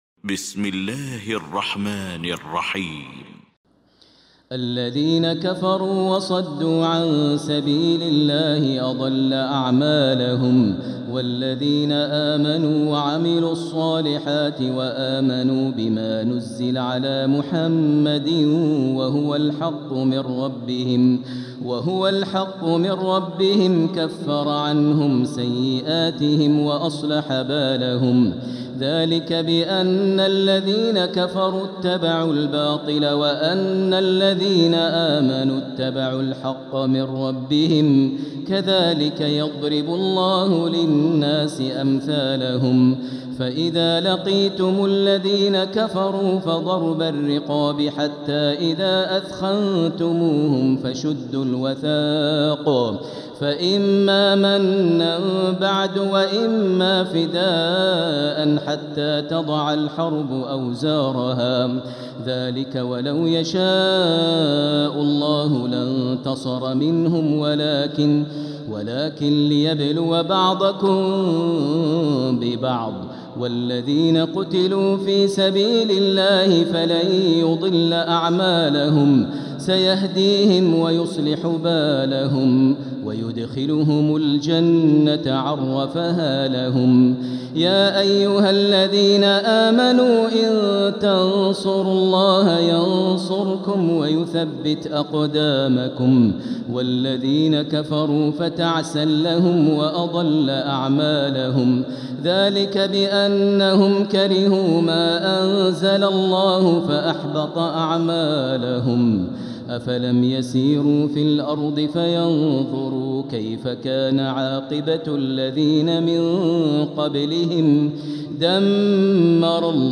المكان: المسجد الحرام الشيخ: فضيلة الشيخ ماهر المعيقلي فضيلة الشيخ ماهر المعيقلي محمد